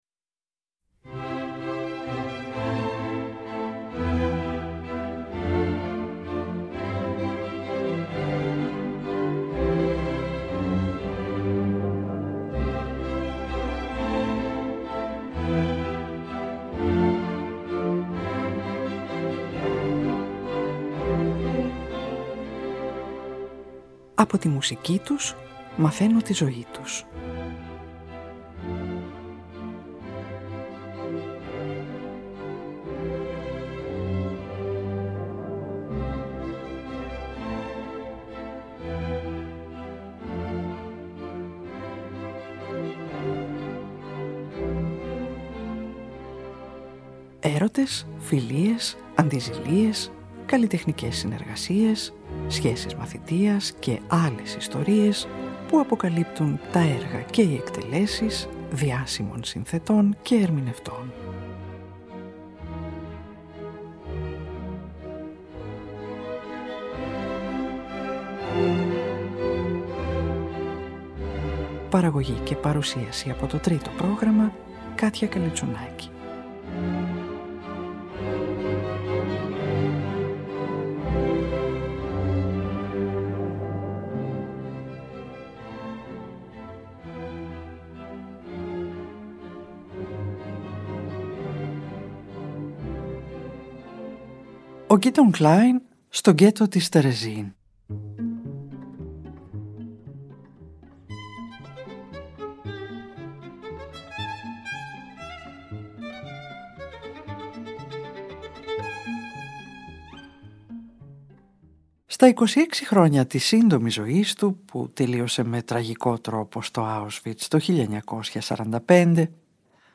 για κουαρτέτο εγχόρδων
για Βιολί, Βιόλα και Βιολοντσέλο